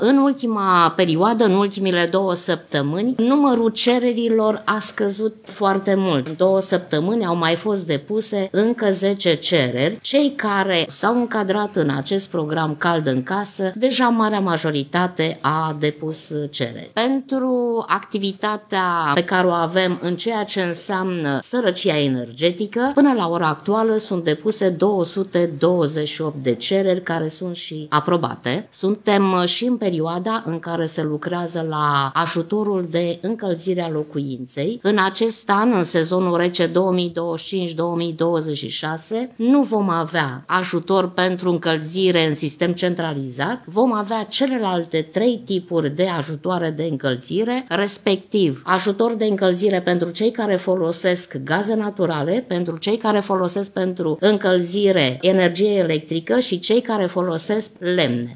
În ședința ordinară a Consiliului Local Tulcea din 30 octombrie 2025, Direcția de Asistență și Protecție Socială Tulcea a prezentat situația programului „Cald în Casă”, prin care familiile vulnerabile primesc sprijin financiar pentru încălzirea locuinței.